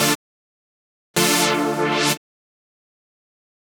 Session 11 - Pad Stab.wav